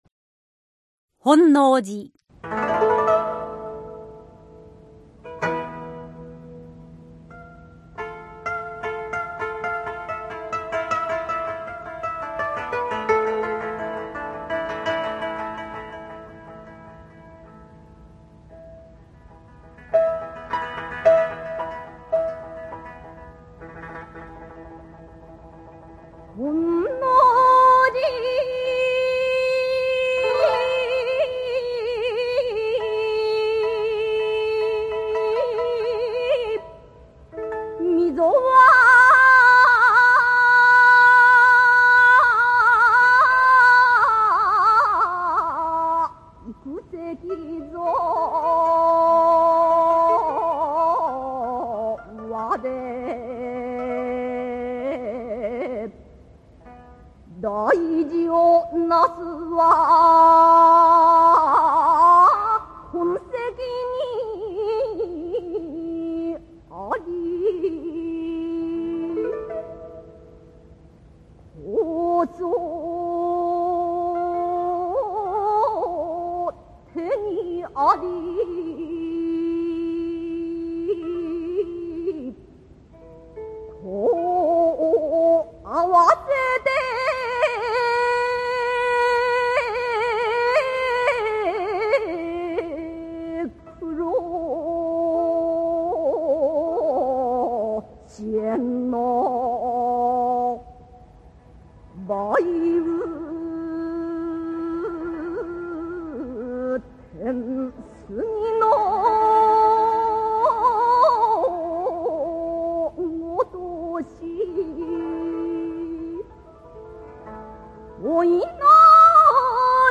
こうした大きなスケールで、この詩を吟じてみたい。